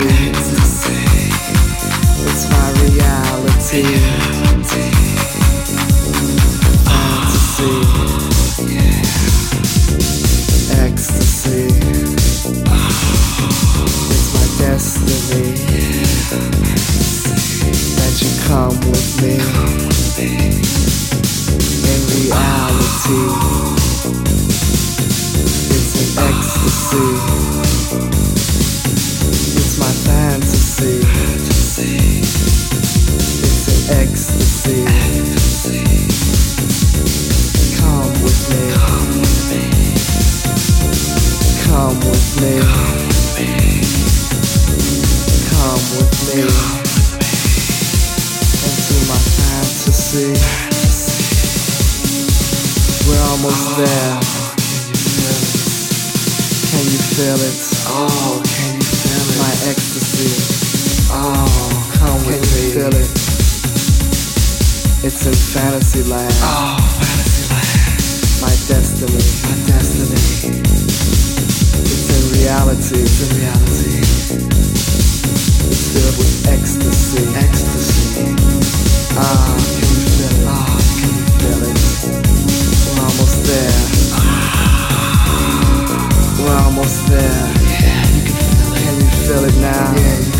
Chicago house classic